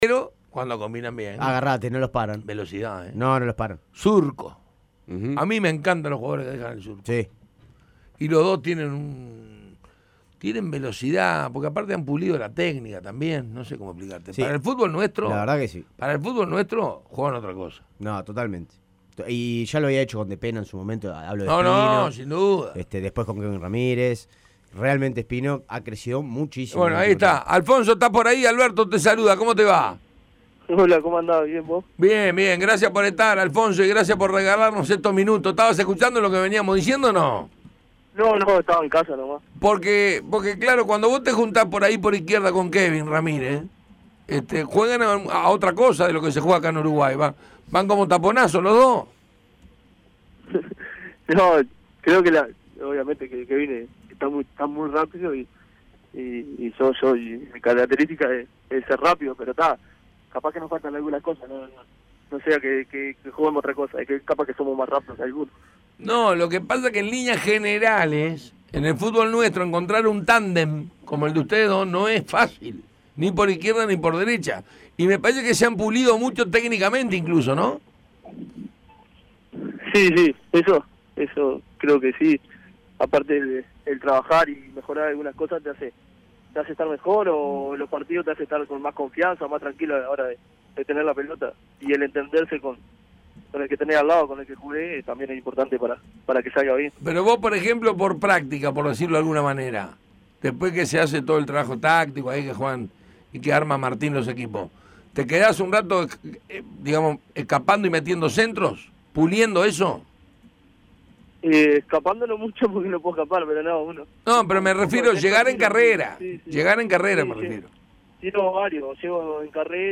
El lateral izquierdo de Nacional, Alfonso "Pacha" Espino, conversó con el panel de Tuya y Mía. Analizó el presente del tricolor, recordó su pasado y expresó su sueño en Nacional y a largo plazo. Escuchá la entrevista completa, distendida, descontracturada y divertida.